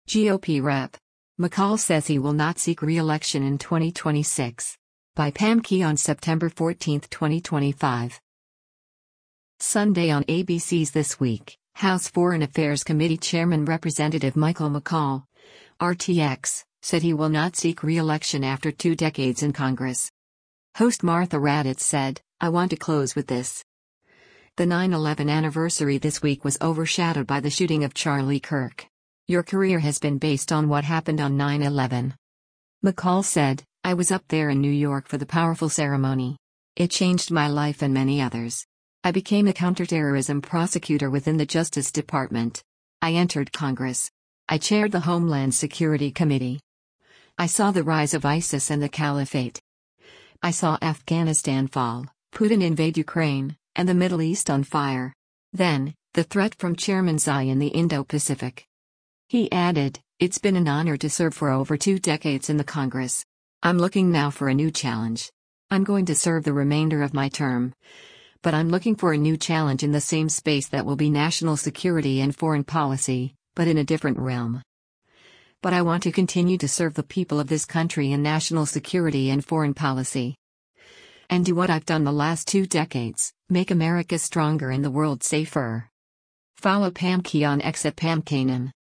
Sunday on ABC’s “This Week,” House Foreign Affairs Committee chairman Rep. Michael McCaul (R-TX) said he will not seek re-election after two decades in Congress.